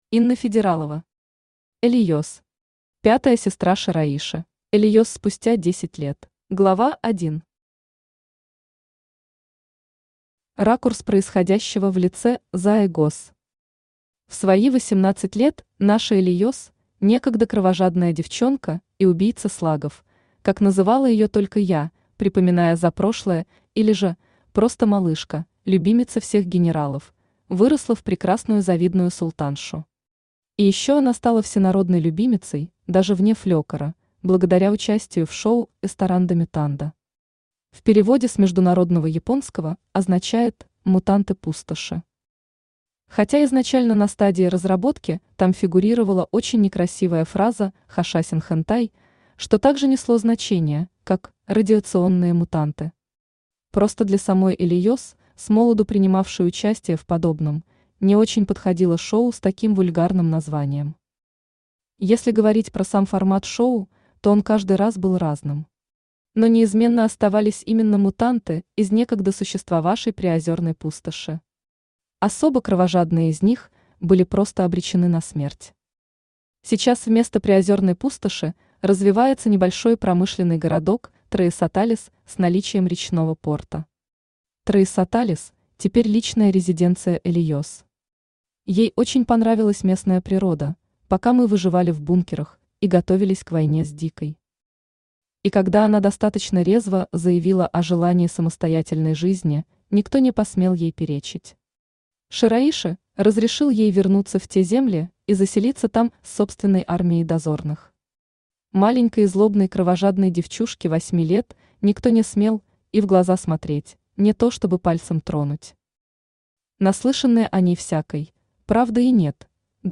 Аудиокнига Элиес. Пятая сестра Шираиши | Библиотека аудиокниг
Пятая сестра Шираиши Автор Инна Федералова Читает аудиокнигу Авточтец ЛитРес.